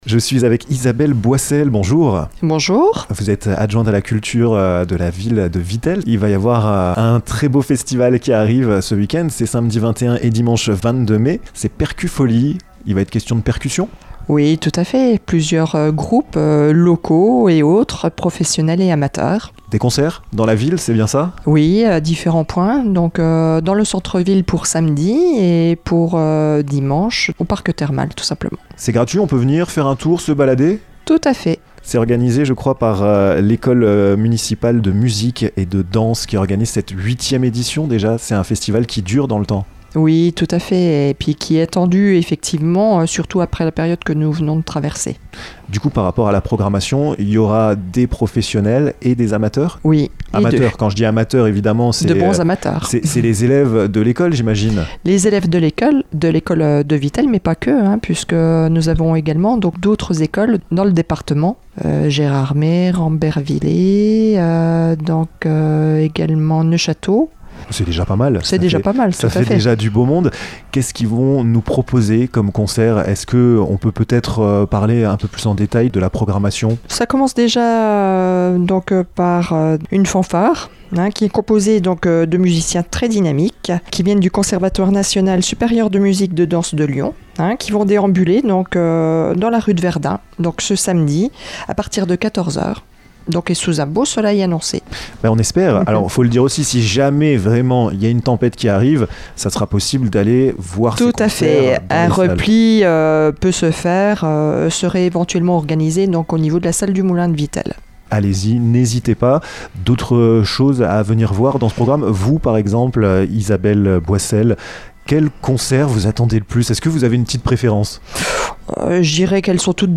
Des concerts dans la ville de Vittel, organisés par les élèves de l'école municipale de musique et de danse, c'est ce que vous réserve ce beau week-end! Isabelle Boissel, adjointe à la culture, a accepté de nous détailler le programme de cette nouvelle édition des Percufolie's!